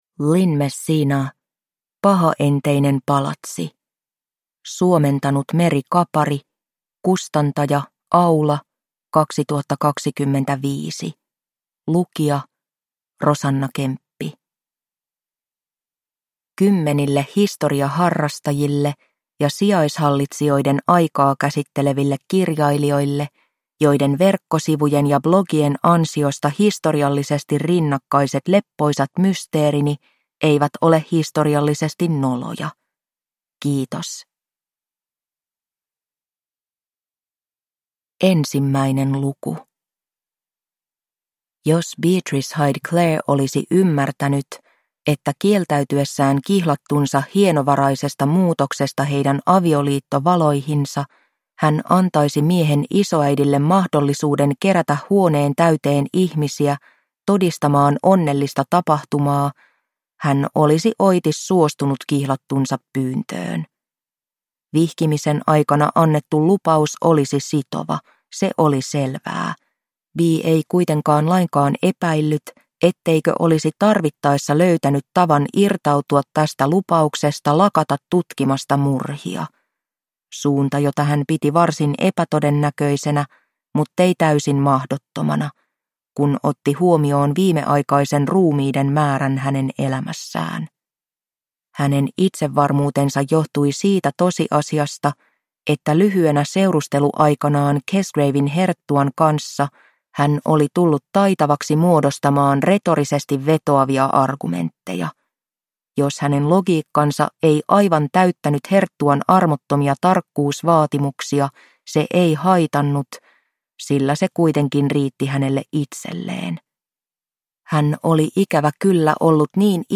Pahaenteinen palatsi – Ljudbok